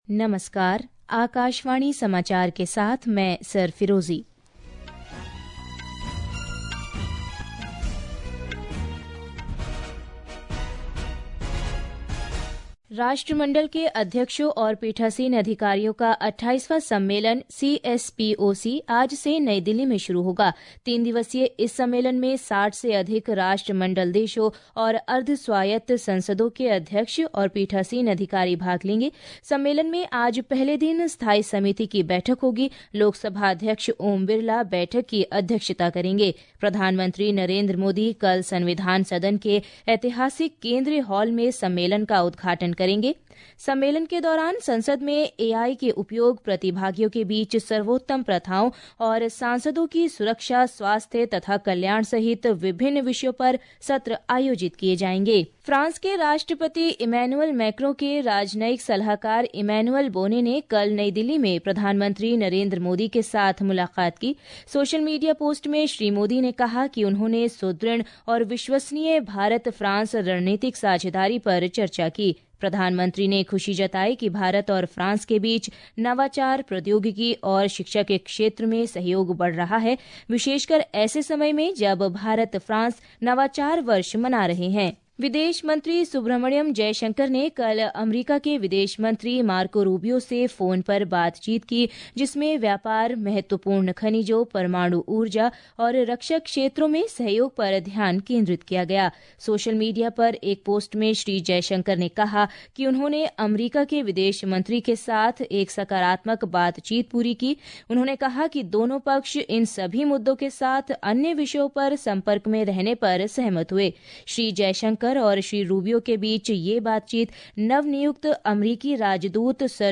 प्रति घंटा समाचार
प्रति घंटा समाचार | Hindi